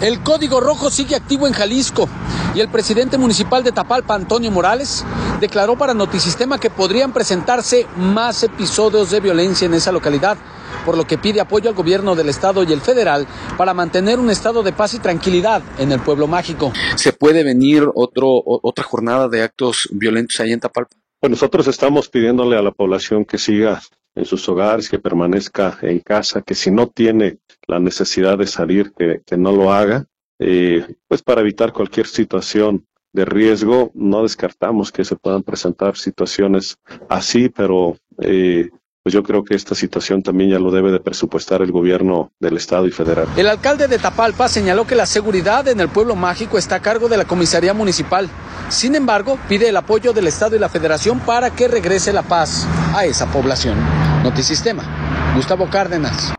audio El Código Rojo sigue activo en Jalisco y el presidente municipal de Tapalpa, Antonio Morales, declaró para Notisistema que podrían presentarse más episodios de violencia en esa localidad, por lo que pide apoyo al Gobierno del Estado y al federal para mantener un estado de paz y tranquilidad en el Pueblo Mágico.